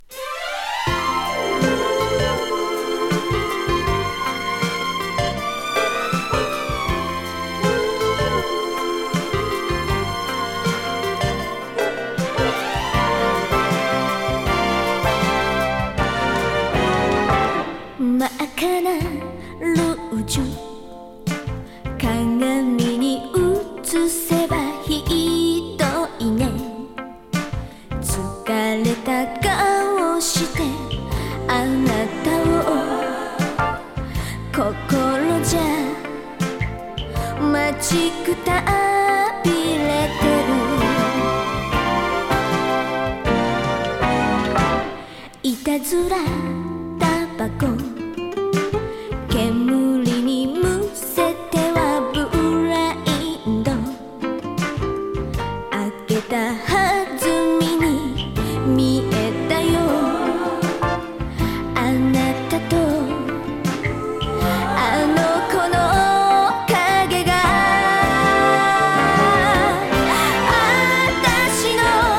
グルーヴィー・インスト・オルガン・ファンク
LA録音。